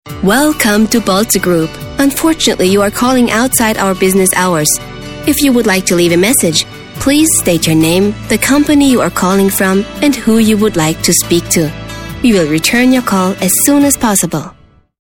Professionelle Sprecherin und Schauspielerin
Sprechprobe: eLearning (Muttersprache):
german female voice over artist, young voice